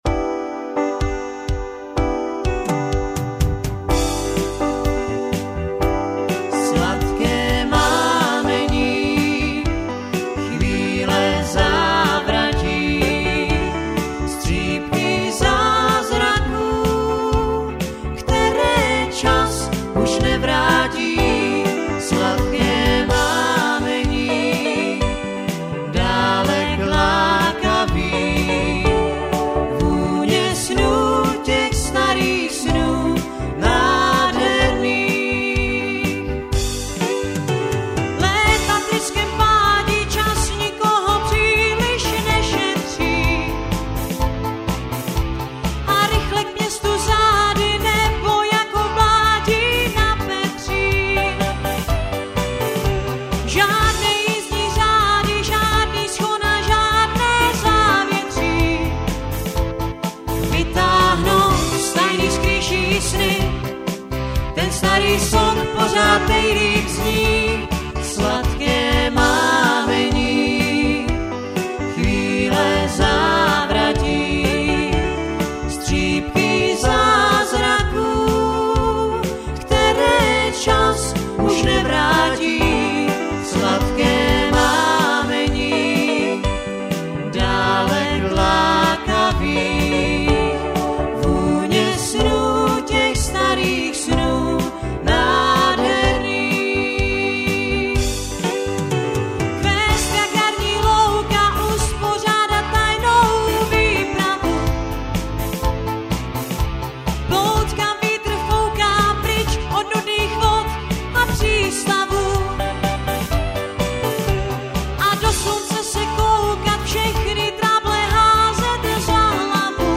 Rychlé